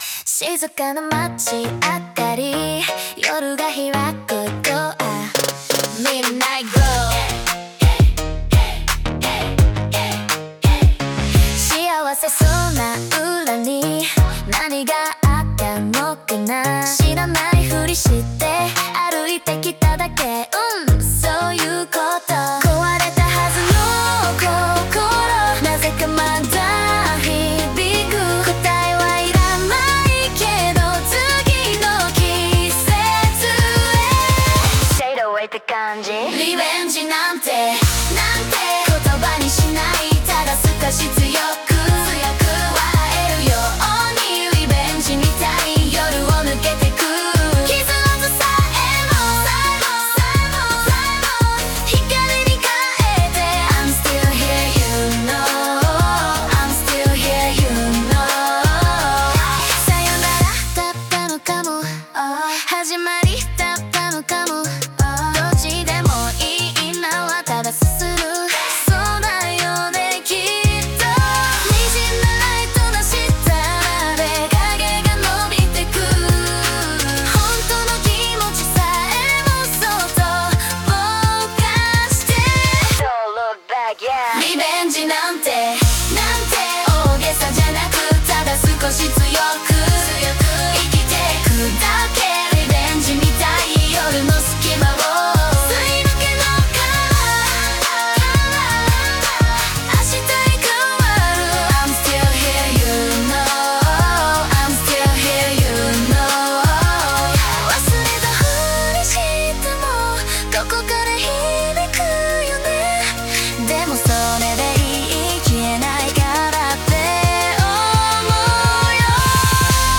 イメージ：ユーロポップ,ドラムンベース,女性ボーカル,切ない,かっこいい,808,